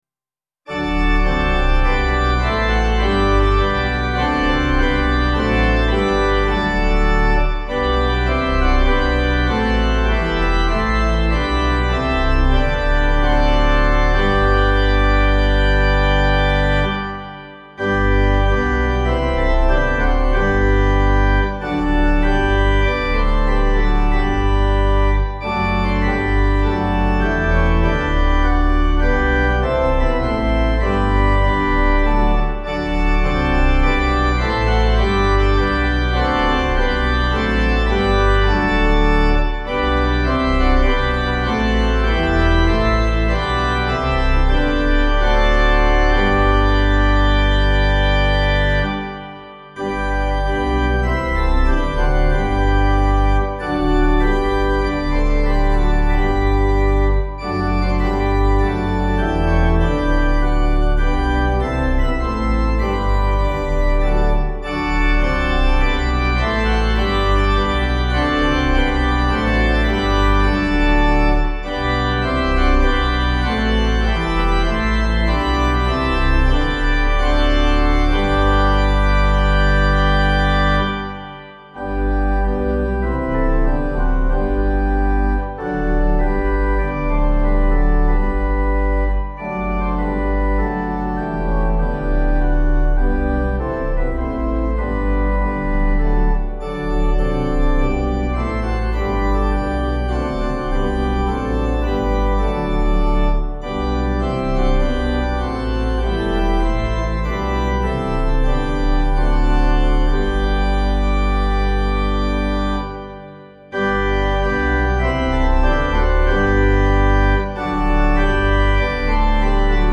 Tune: ST. IGNATIUS (Beaumont)
Key : A♭ Major